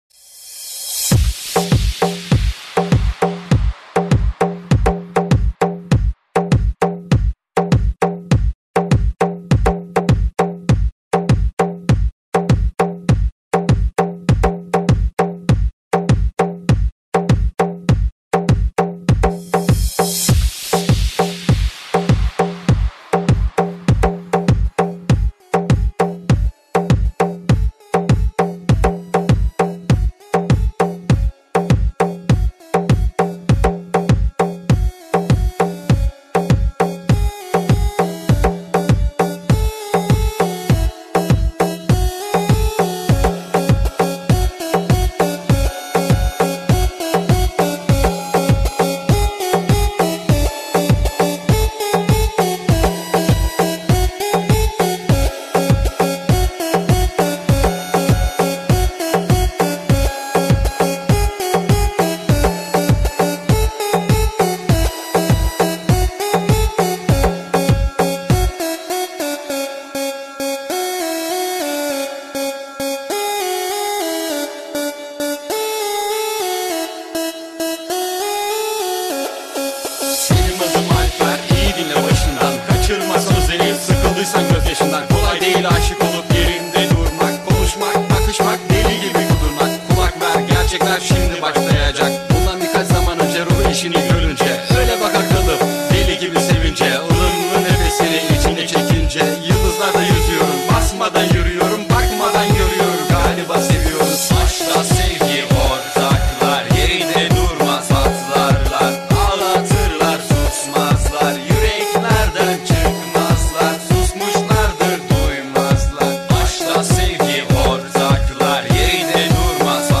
это яркий пример синтеза поп и электронной музыки